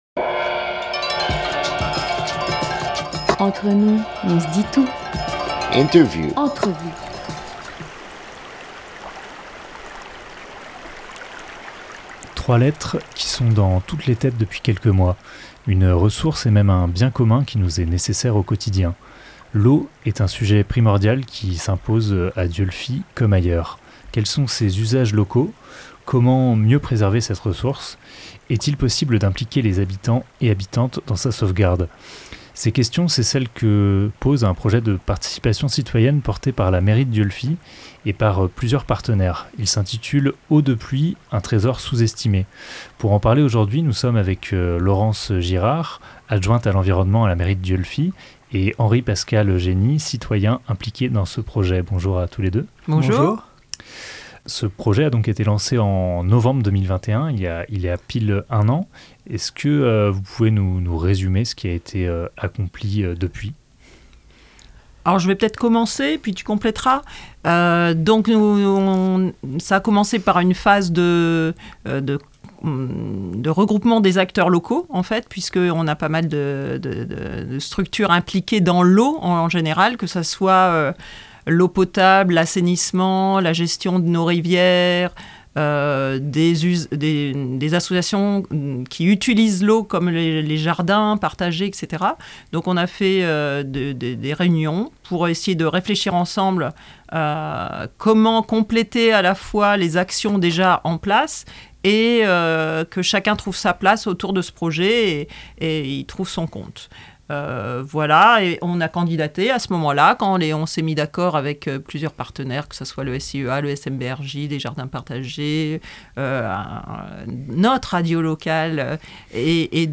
16 novembre 2022 11:11 | Interview